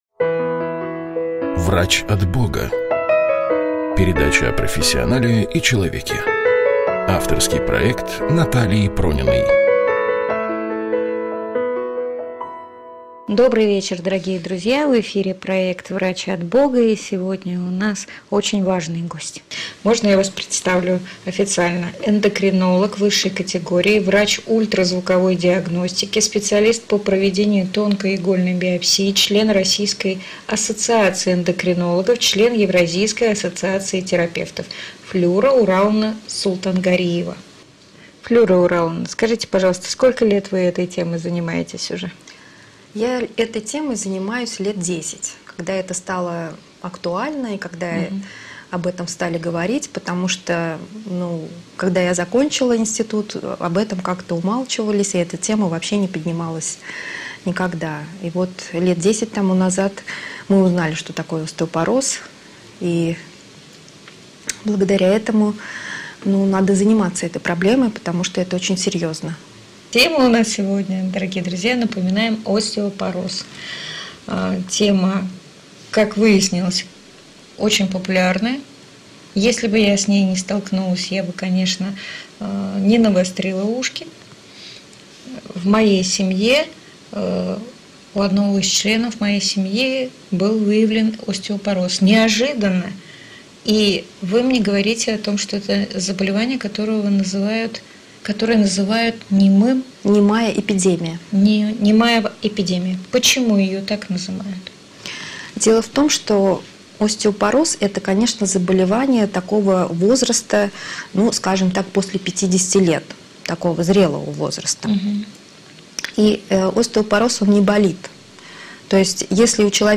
В передыче использованы фрагменты программ с участием врачей-эндокринологов.